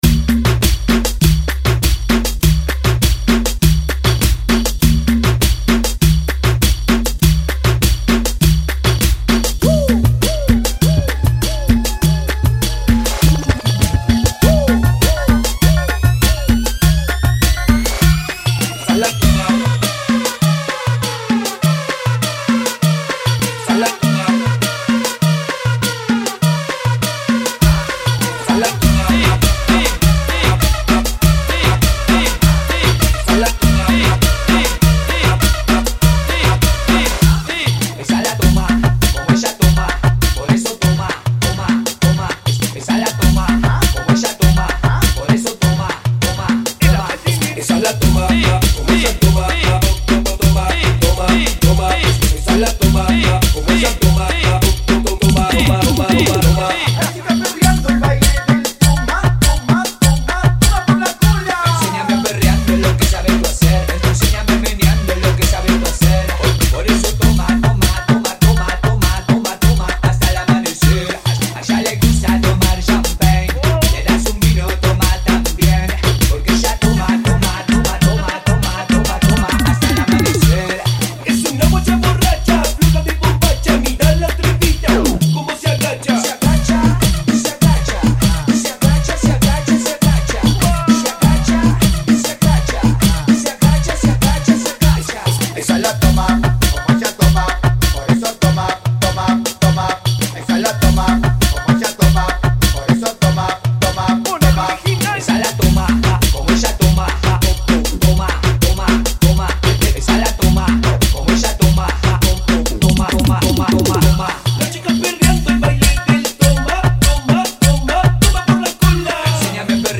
Funky [ 100 Bpm ]